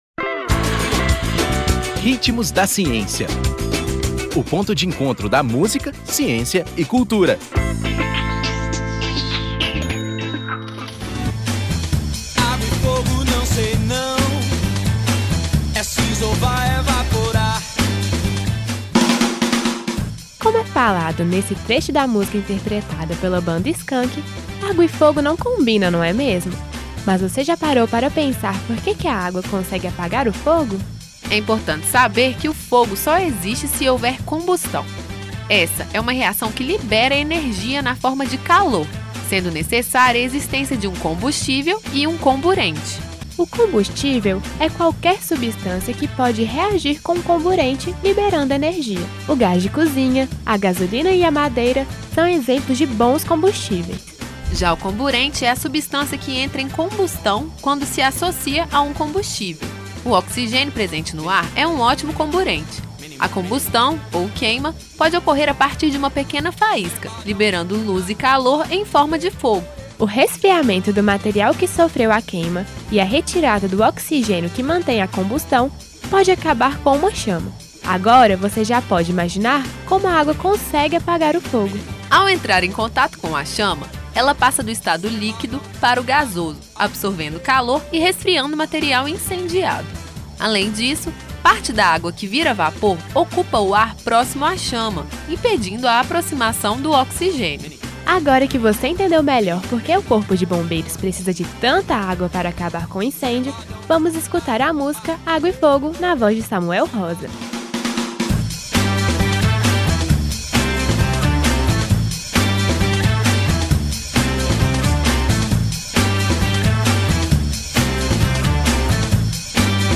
Como é falado nesse trecho da música interpretada pela banda Skank, água e fogo não combinam, não é mesmo? Mas você já parou para pensar por que a água consegue apagar o fogo?…Ouça todo o programa:
Intérprete: Skank